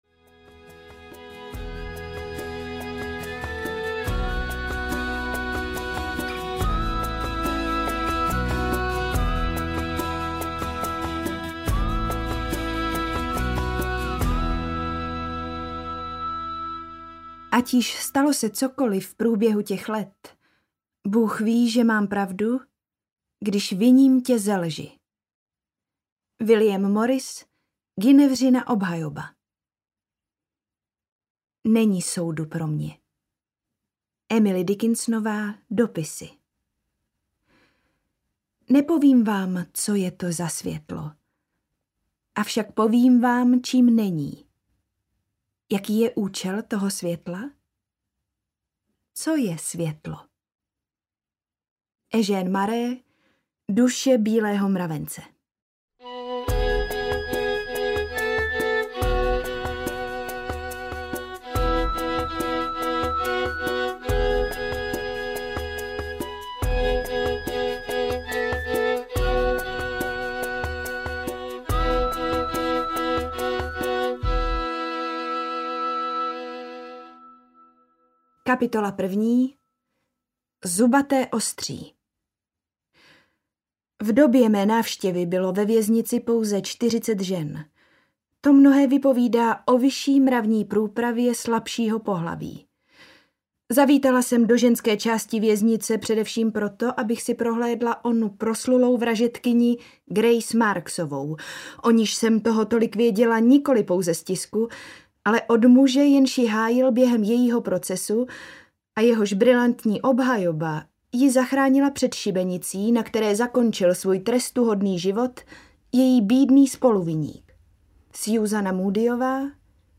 Alias Grace audiokniha
Ukázka z knihy